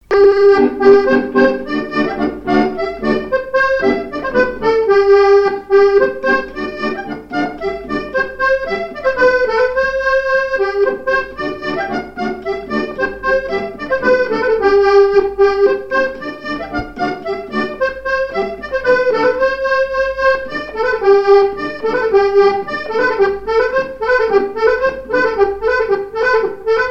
Mémoires et Patrimoines vivants - RaddO est une base de données d'archives iconographiques et sonores.
Saint-Gervais
danse : ronde : grand'danse
airs de danse à l'accordéon diatonique
Pièce musicale inédite